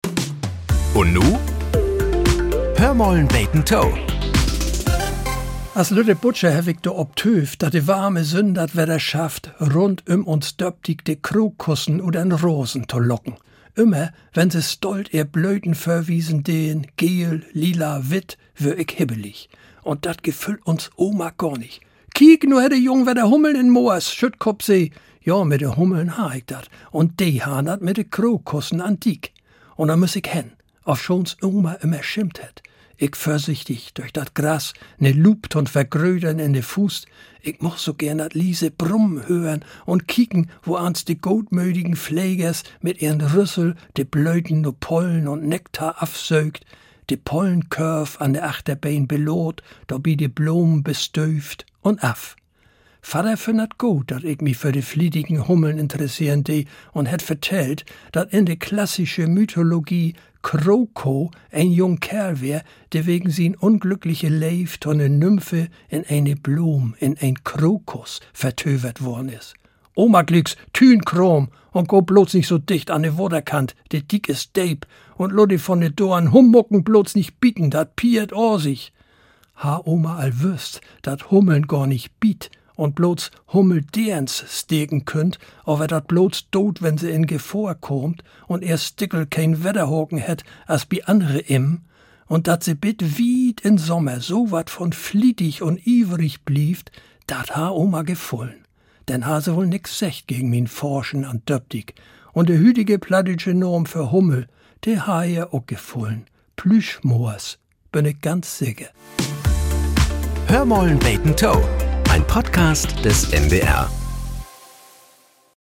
Nachrichten - 27.02.2025